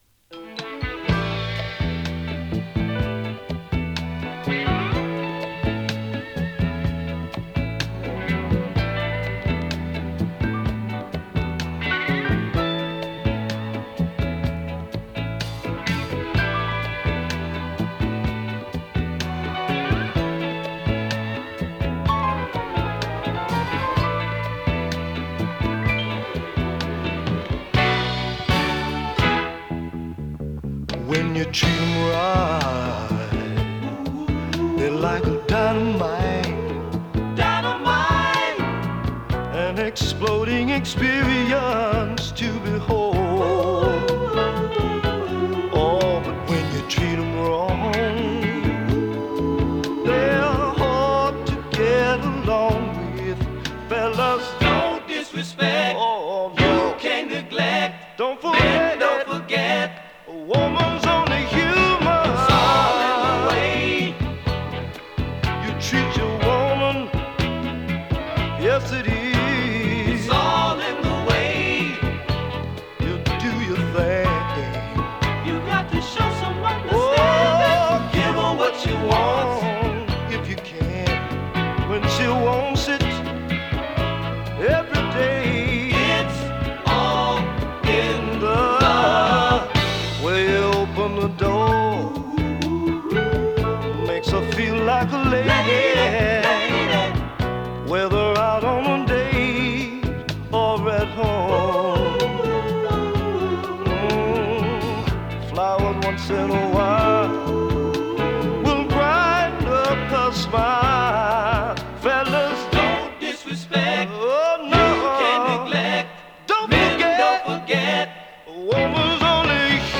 フィリーソウル
多彩なコーラス・ワークがすばらしいスウイート/フィリーソウル！